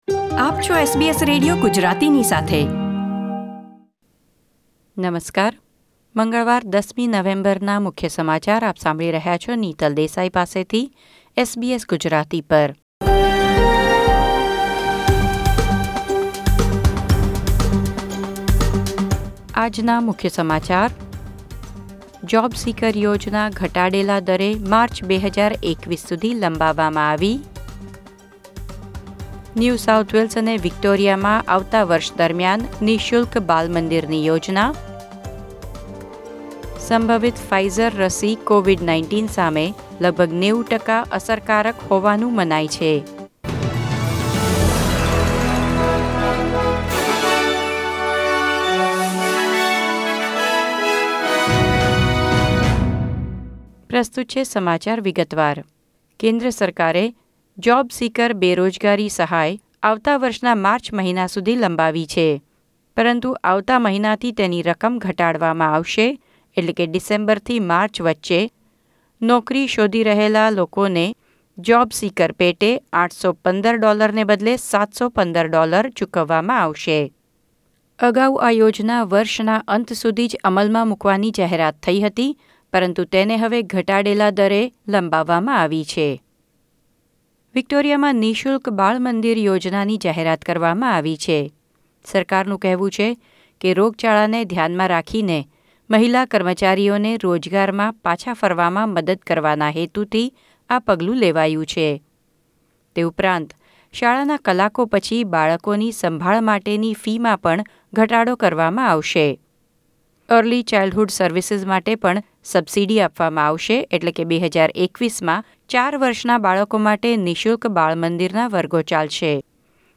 SBS Gujarati News Bulletin 10 November 2020